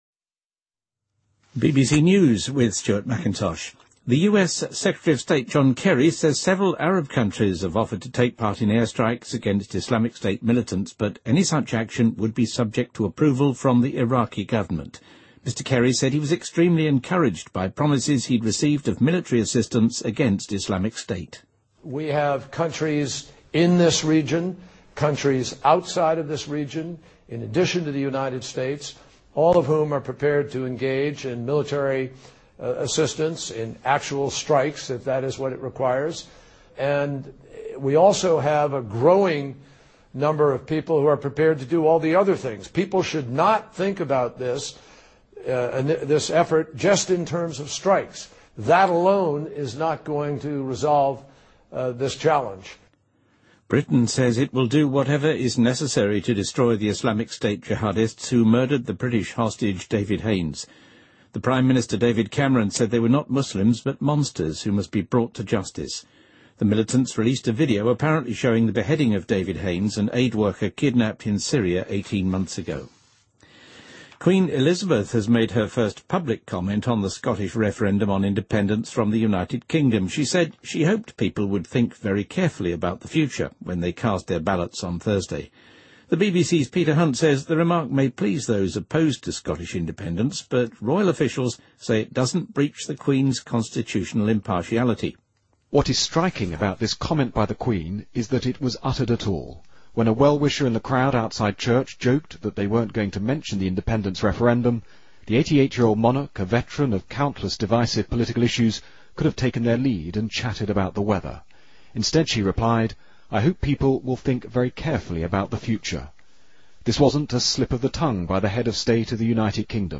BBC news,韩国三星LG因洗衣机起纠纷